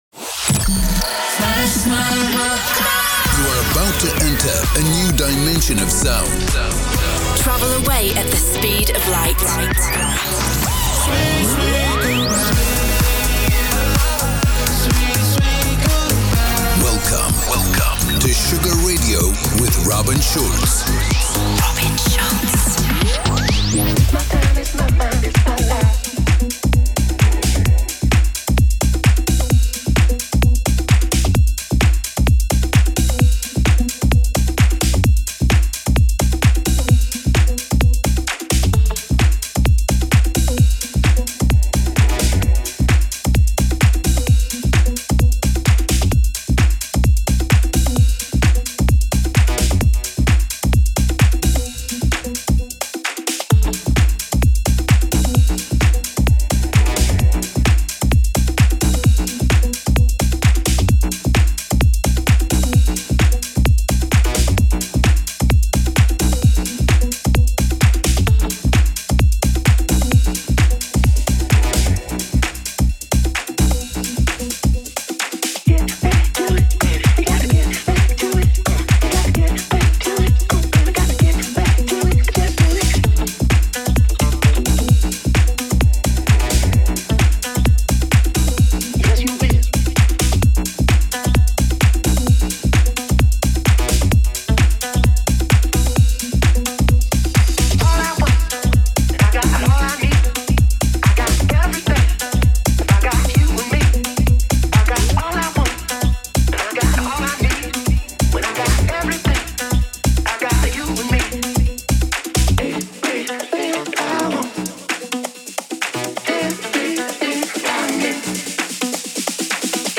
music DJ Mix in MP3 format
Genre: Electro Pop